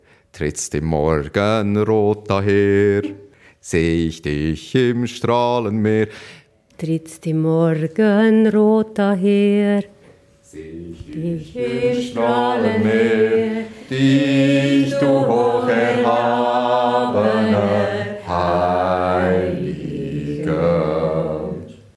A few Swiss sing a few lines
Swiss singing anthem in German